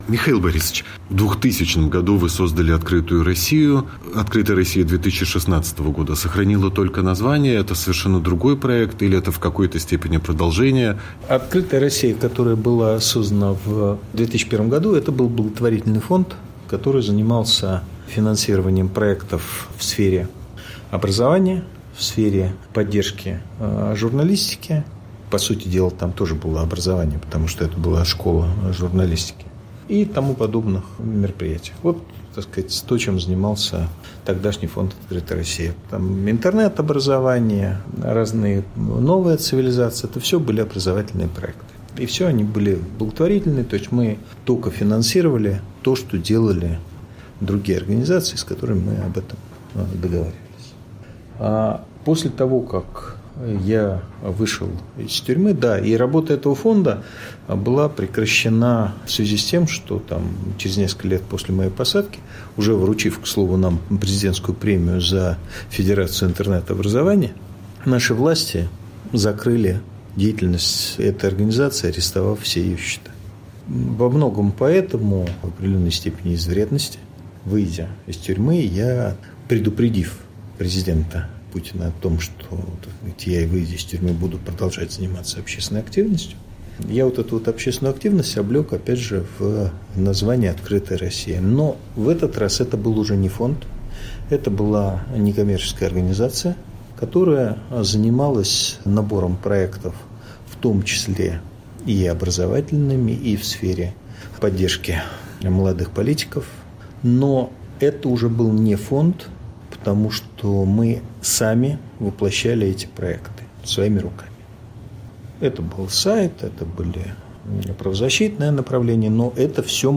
Михаил Ходорковский ответил на вопросы Радио Свобода о движении "Открытая Россия", мести Кремлю и мести Кремля, правом повороте, эмиграции и слежке, Дональде Трампе и о своем коте.